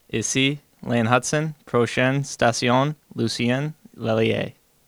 Les joueurs des Canadiens ont prêté leurs voix à la Société de transport de Montréal (STM) pour annoncer les arrêts sur la ligne orange à proximité du Centre Bell.